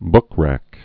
(bkrăk)